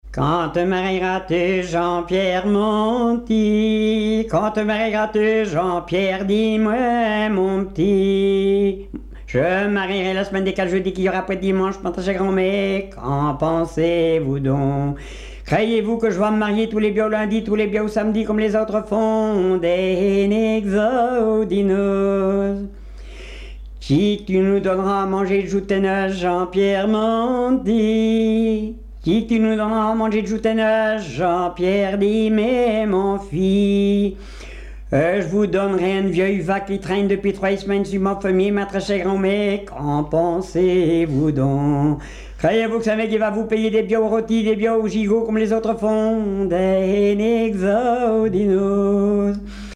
Enquête dans les Résidences de personnes âgées du Havre
Témoignages et chansons populaires
Pièce musicale inédite